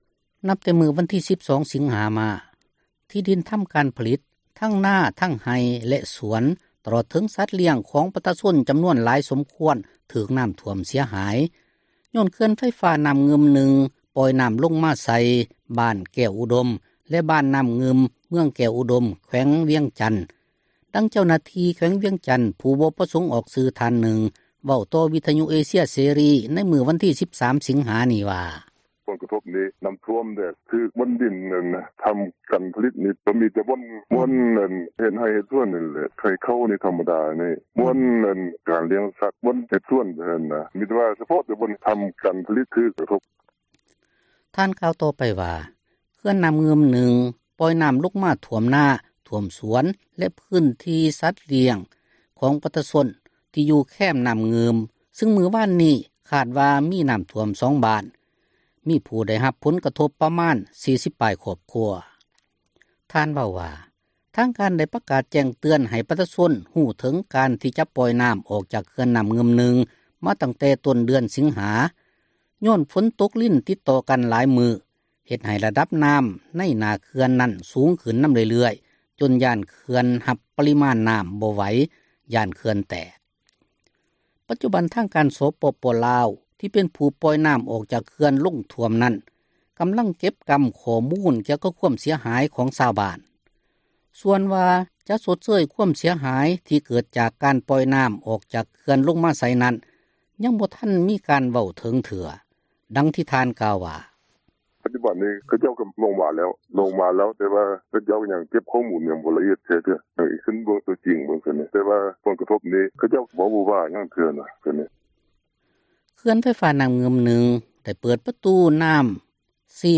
ນັບແຕ່ວັນທີ 12 ສິງຫາ ມານີ້ ພື້ນທີ່ດິນທໍາການຜລິດ ທັງນາທັງໄຮ່ແລະຮົ້ວສວນ ຕລອດເຖິງສັດລ້ຽງຂອງປະຊາຊົນ ຈໍານວນຫຼວງຫຼາຍ ຖືກນໍ້າຖ້ວມເສັຍຫາຍ ຍ້ອນເຂື່ອນໄຟຟ້ານໍ້າງື່ມ 1 ປ່ອຍນໍ້າລົງມາໃສ່ ບ້ານແກ້ວອຸດົມ ແລະບ້ານນໍ້າງື່ມ ເມືອງແກ້ວອຸດົມ ແຂວງວຽງຈັນ; ດັ່ງເຈົ້າໜ້າທີ່ ແຂວງວຽງຈັນ ຜູ້ບໍ່ປະສົງອອກຊື່ ທ່ານນຶ່ງ ເວົ້າຕໍ່ວິທຍຸເອເຊເຊັຍເສຣີ ໃນມື້ວັນທີ 13 ສິງຫາ ນີ້ວ່າ: